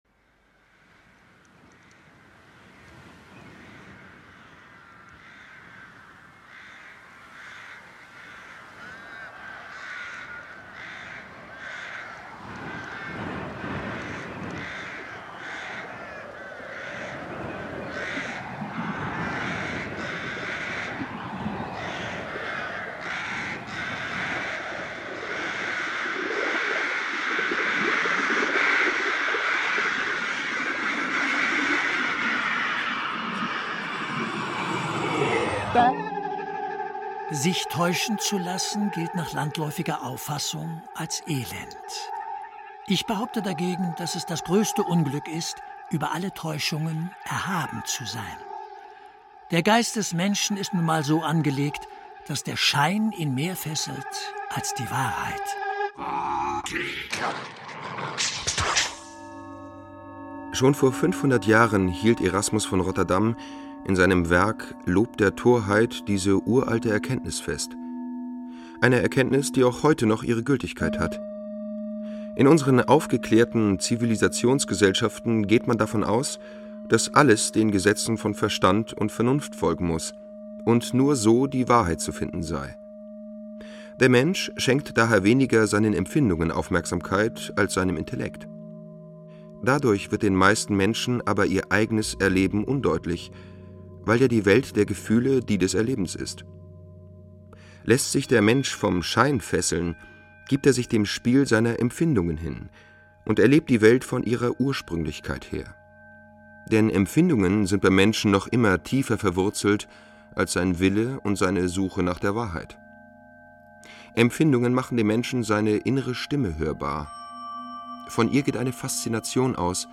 Audio des Hörstücks